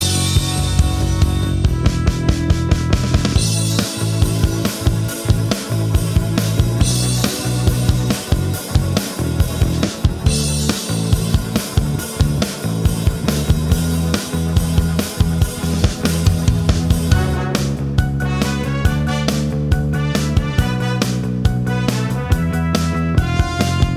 No Guitars Pop (2010s) 3:39 Buy £1.50